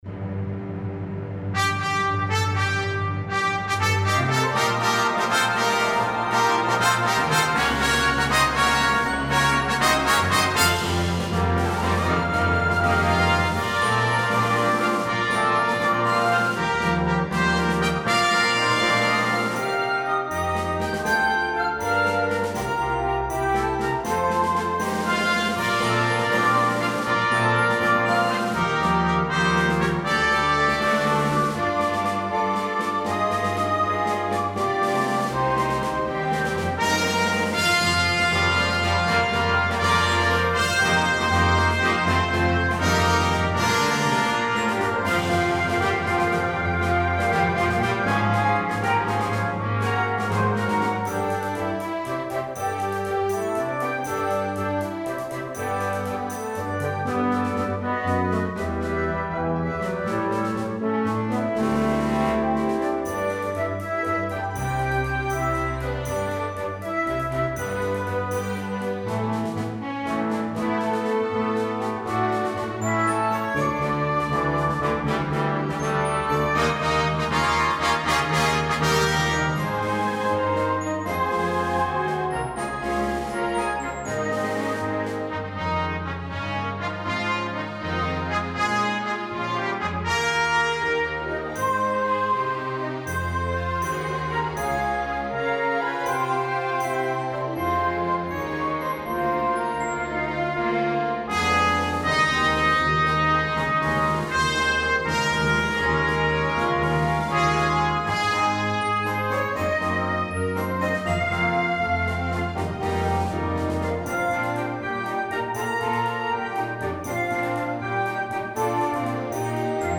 The setting is triumphant and energetic.